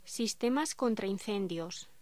Locución: Sistemas contra incendios
voz